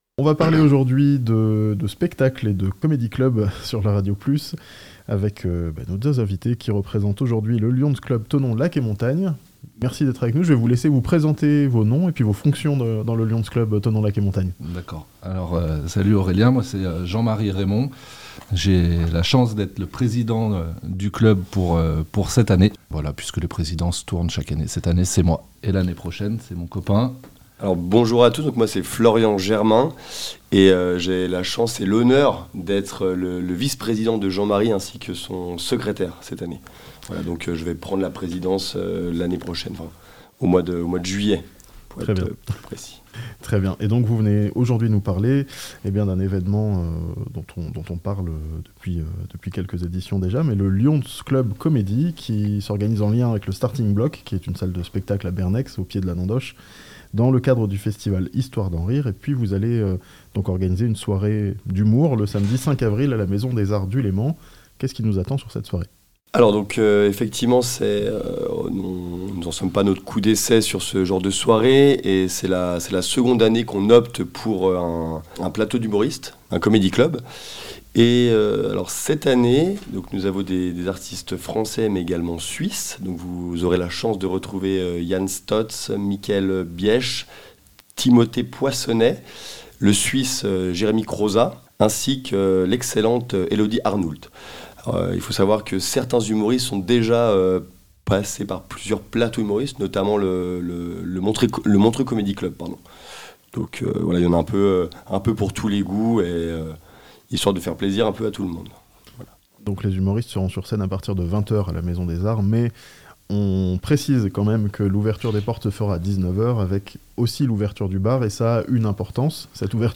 Un comedy club, pour la bonne cause, à Thonon (interview)